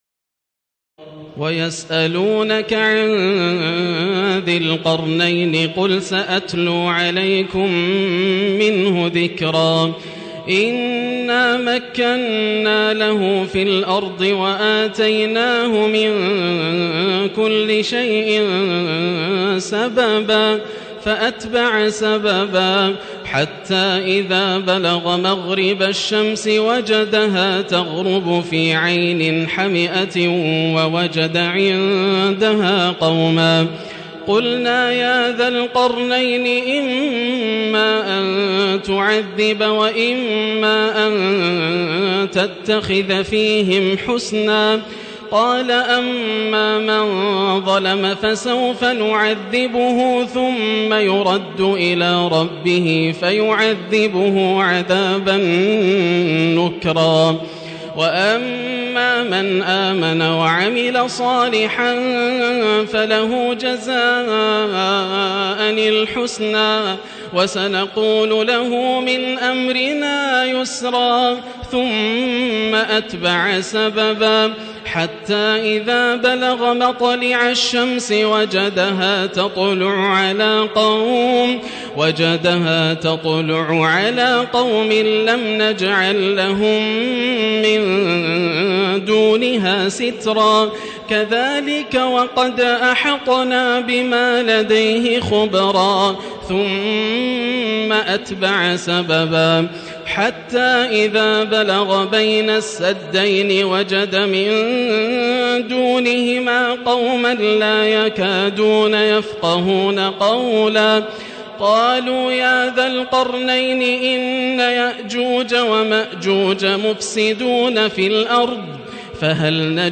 تراويح الليلة الخامسة عشر رمضان 1437هـ من سورتي الكهف (83-110) و مريم كاملة Taraweeh 15 st night Ramadan 1437H from Surah Al-Kahf and Maryam > تراويح الحرم المكي عام 1437 🕋 > التراويح - تلاوات الحرمين